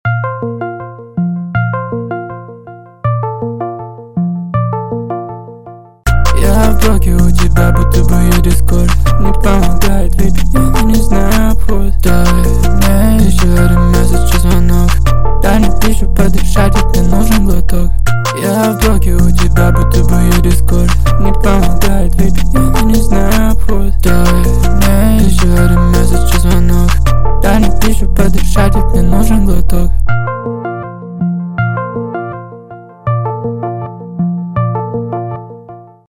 2024 » Русские » Рэп Скачать припев